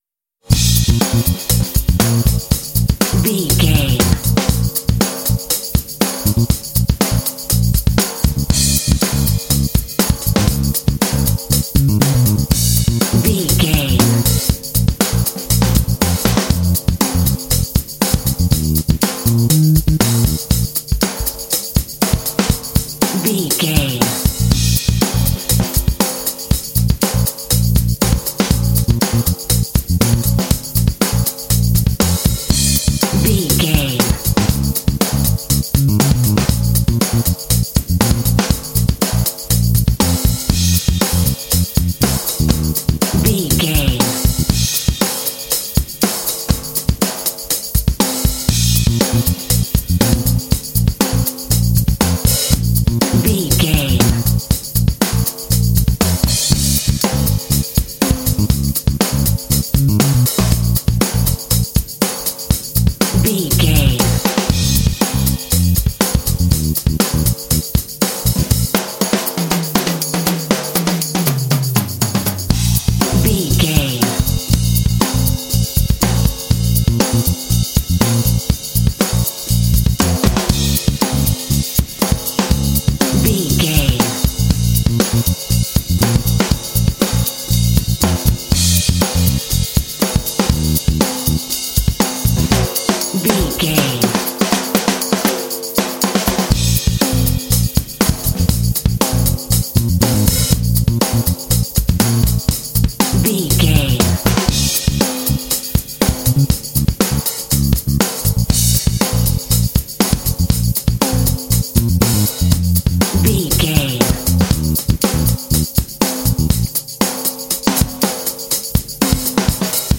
Uplifting
Aeolian/Minor
B♭
groovy
cheerful/happy
driving
drums
percussion
bass guitar
jazz